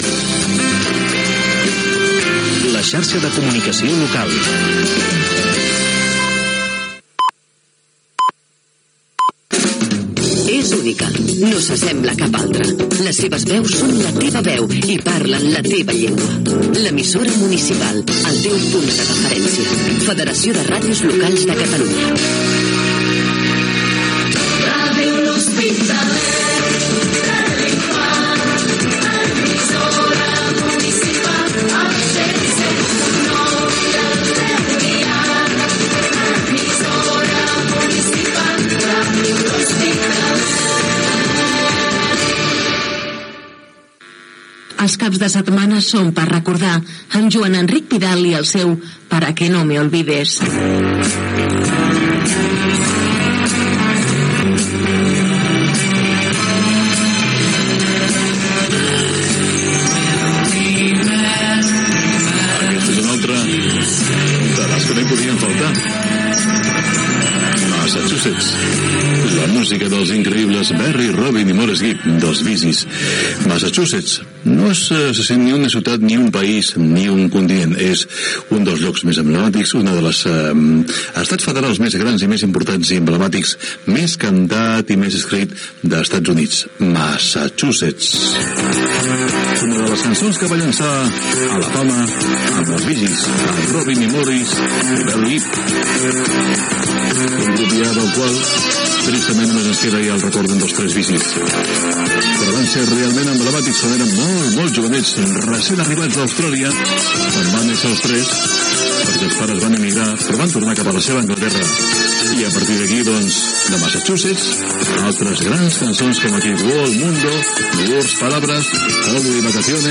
Indicatius de la Xarxa de Comunicació Local, de la Federació de Ràdios Locals de Catalunya i de la ràdio. Careta del programa, tema musical i presentació
Musical